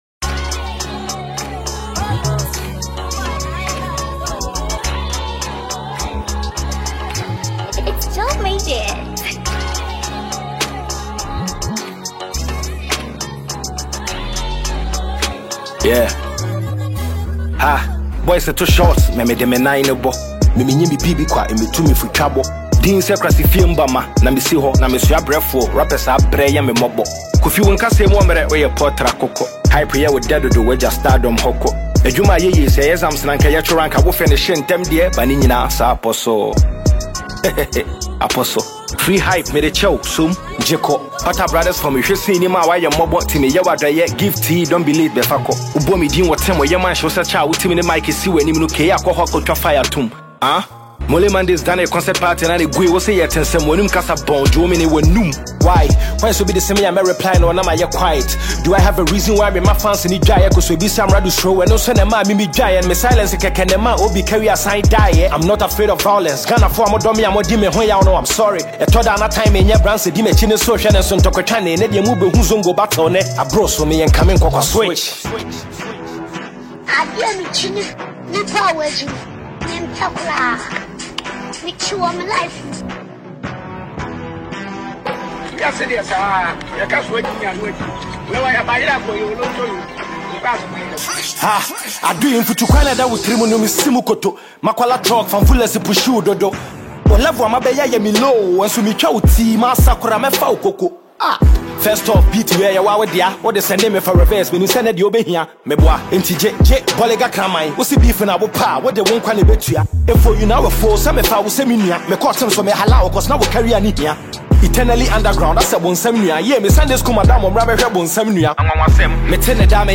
Ghanaian rapper
diss song